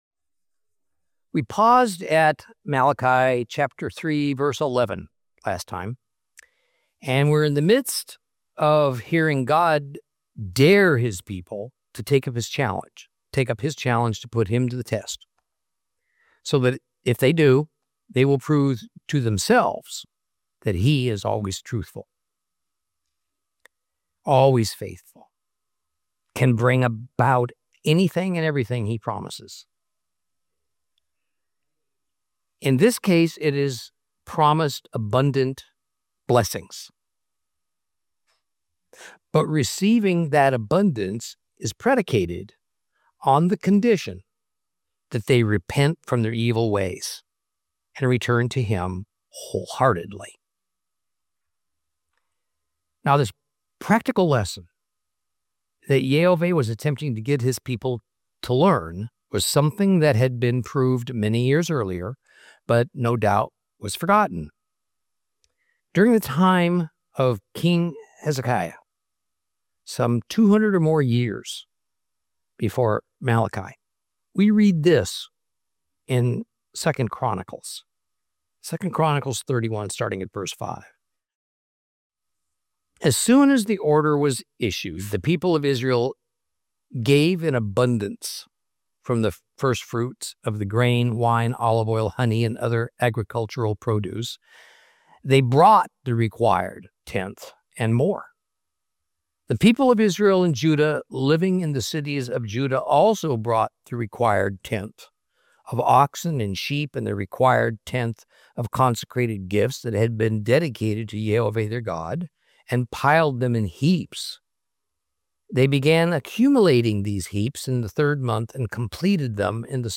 Teaching from the book of Malachi, Lesson 12 Chapter 3 continued 3.